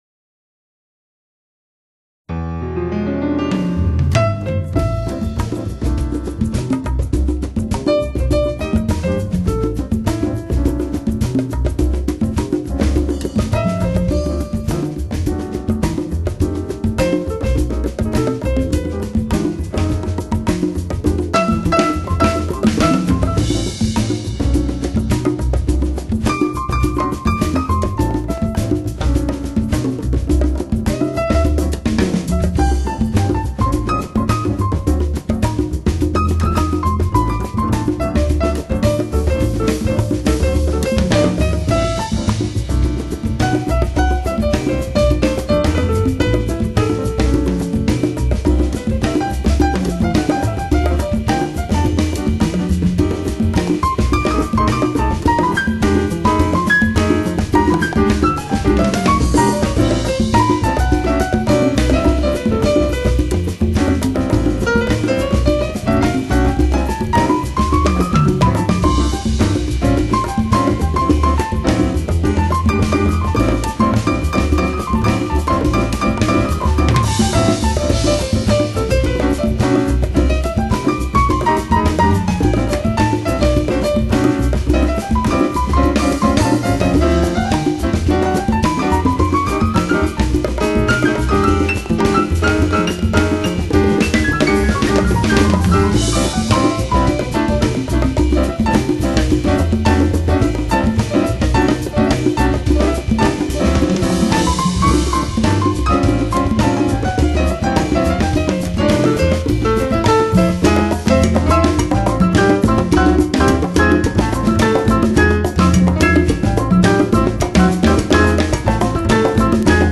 Piano
Bass
Drums
Timbales & Vocals
Congas & Vocals